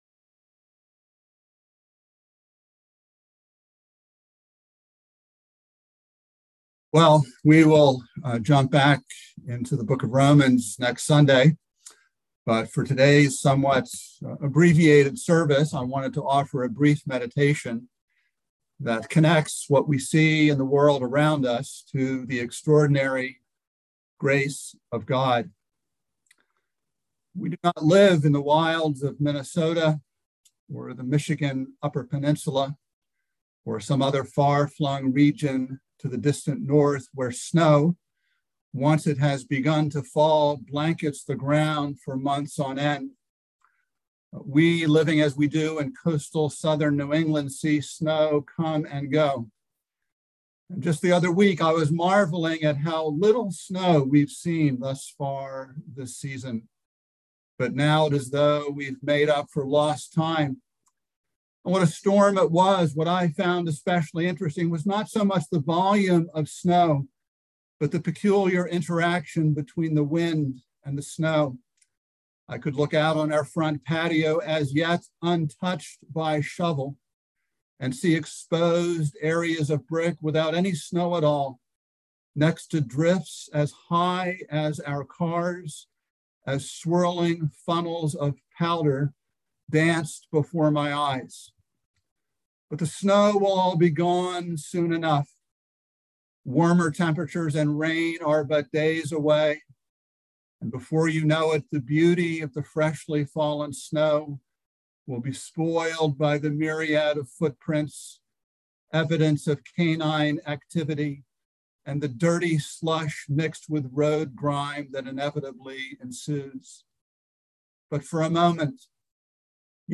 by Trinity Presbyterian Church | Jan 30, 2022 | Sermon